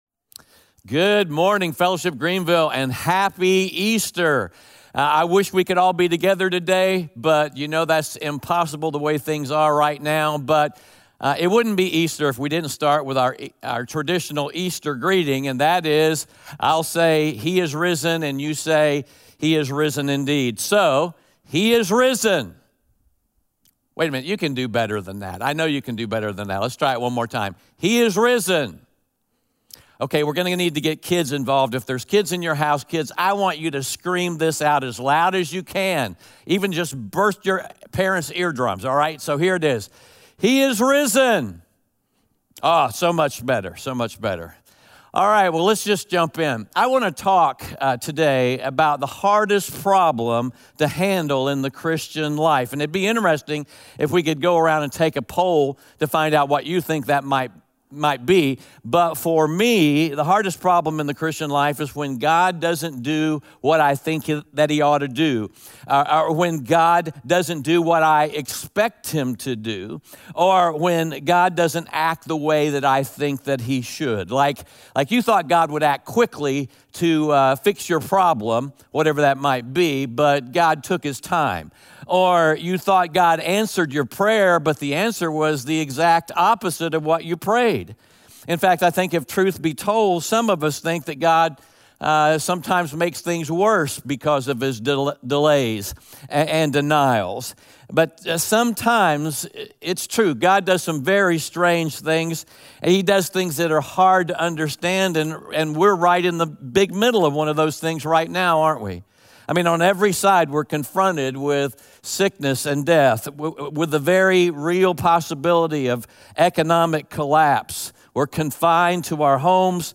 John 11 Audio Sermon Notes (PDF) Ask a Question John 11 opens on a family scene.